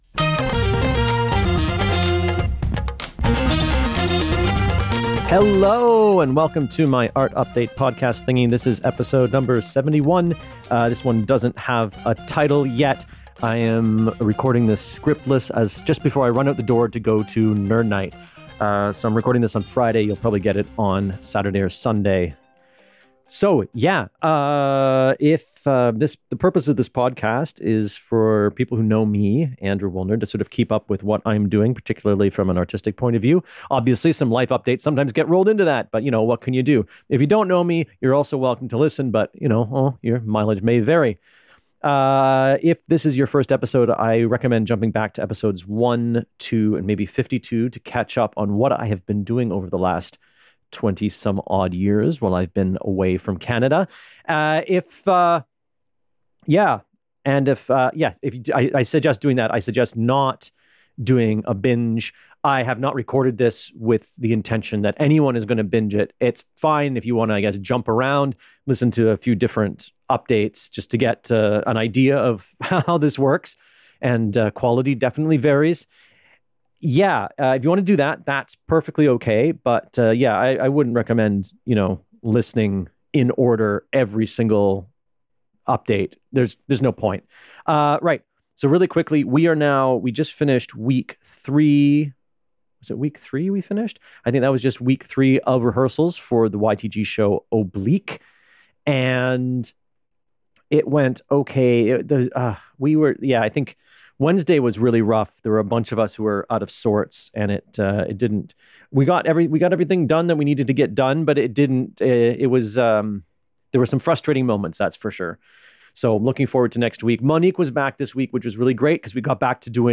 Unscripted this week, I give the wrong update number in the intro and talk about week 3 of the YTG rehearsals.